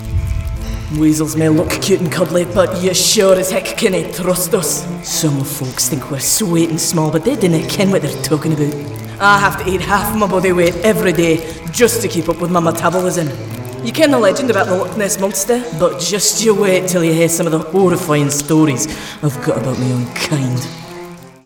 Her sound is very easy on the Ear. She also has an ability to cover a very wide age range and creates fantastically full and rich characters completely different from one to the other perfect for Gaming and Animation.